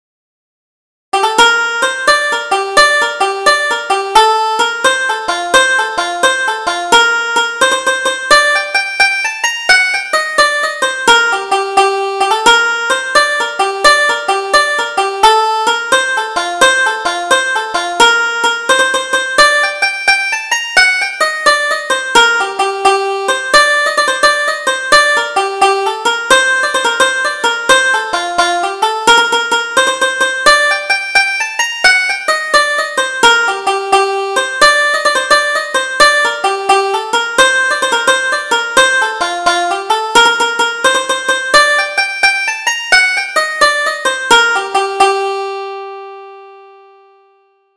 Double Jig: The Day after the Fair